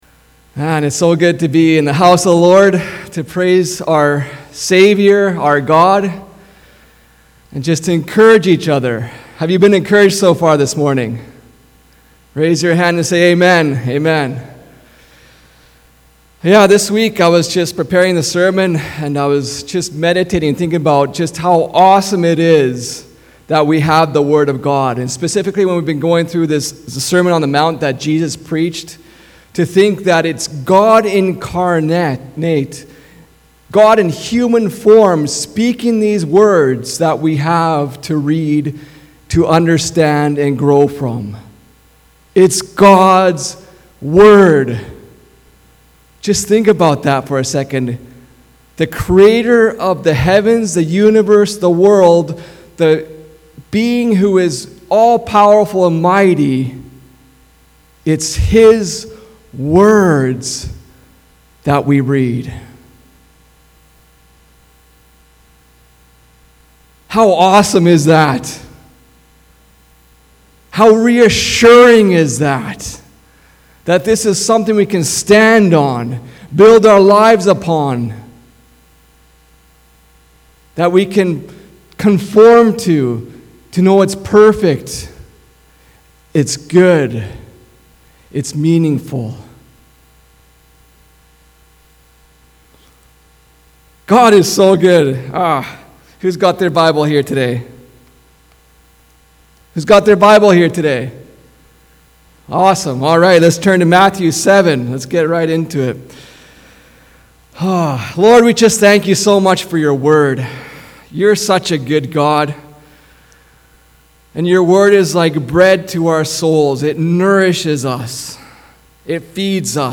Sermons | 19twenty church